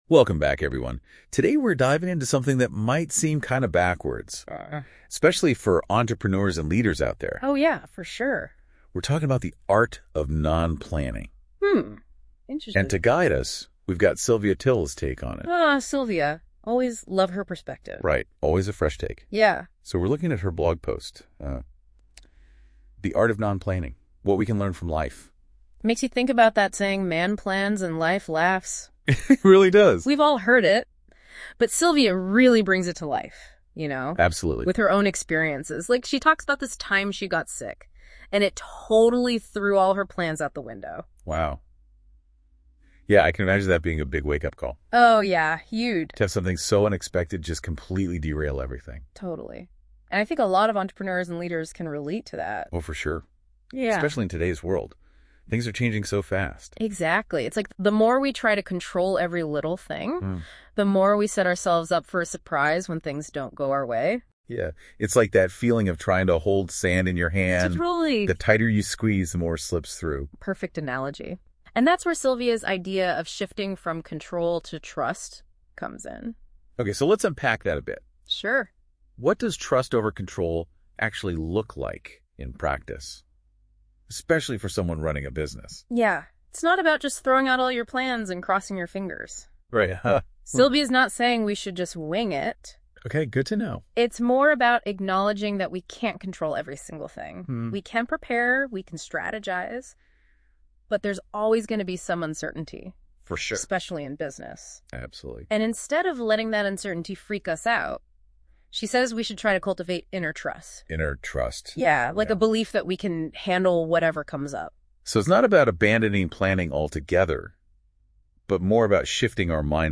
Hier eine kleine Spielerei für dich: KI hat aus diesem Beitrag einen englischen Podcast gemacht!